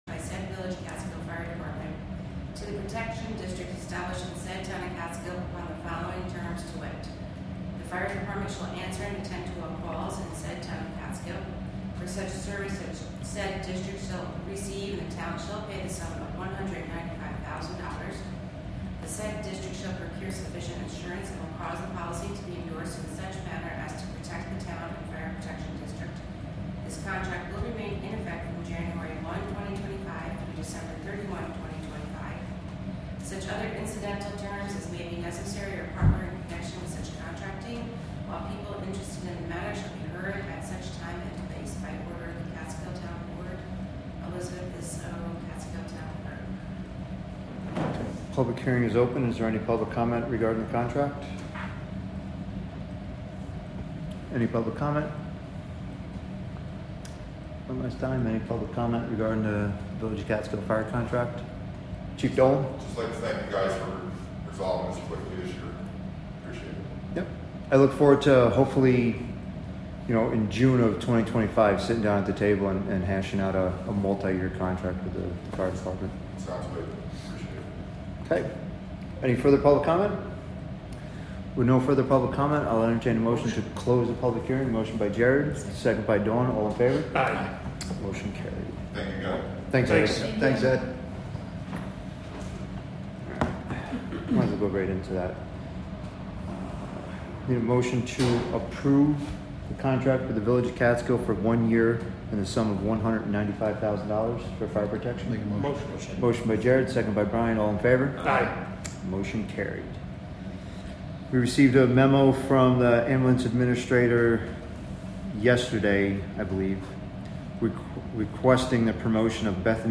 Live from the Town of Catskill: September 18, 2024 Catskill Town Board Meeting (Audio)